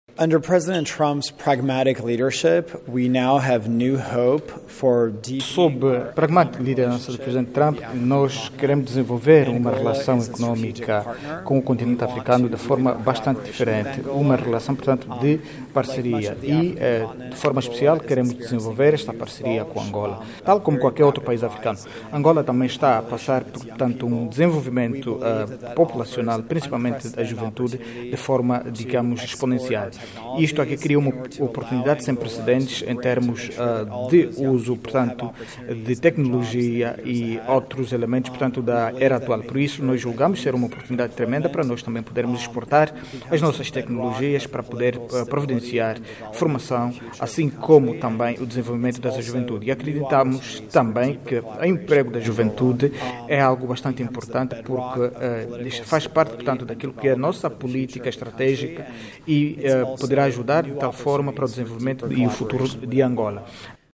Em declarações aos jornalistas, Jacob Helberg disse que, tal como acontece com muitos países do continente africano, Angola está a registar crescimento muito rápido da população sobretudo de jovens, facto que oferece uma oportunidade para os Estados Unidos exportarem tecnologias para permitir que o país garanta que todos estes jovens tenham oportunidades de emprego nos próximos anos.